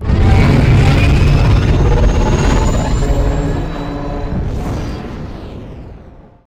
takeoff_1.wav